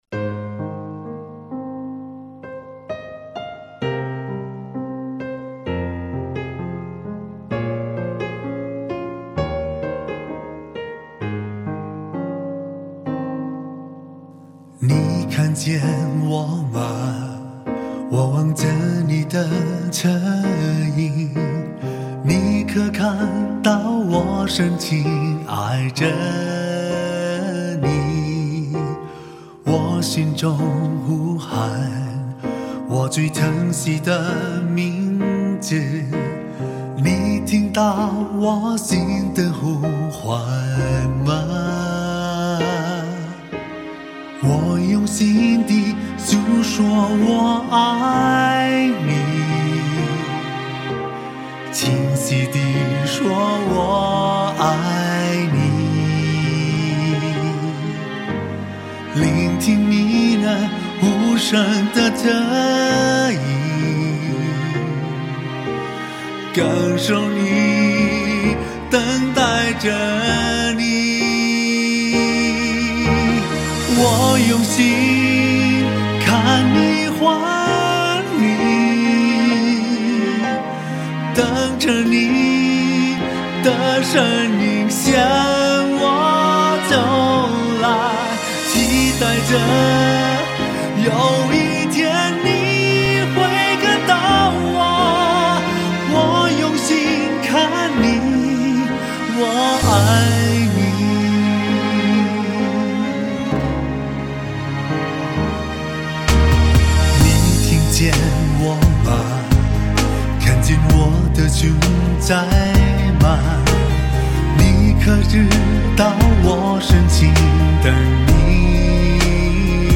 男声中文版